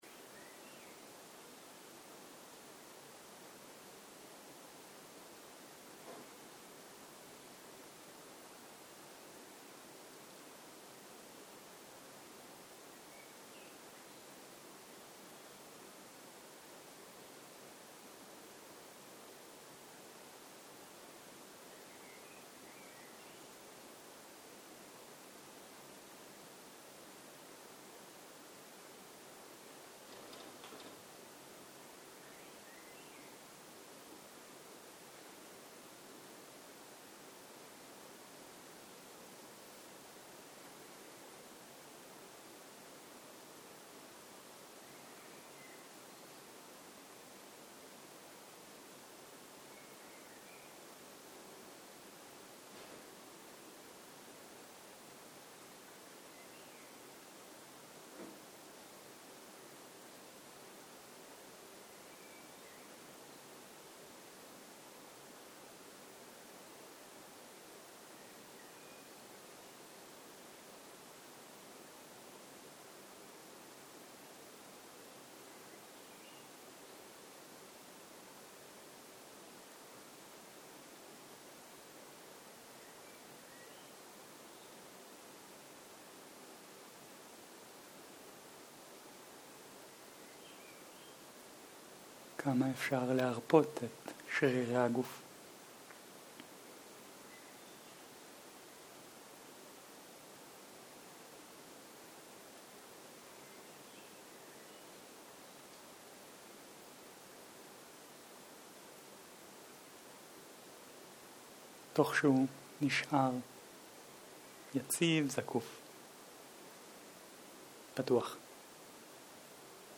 צהריים - מדיטציה מונחית - רגישות לגוף ומעבר לו
סוג ההקלטה: מדיטציה מונחית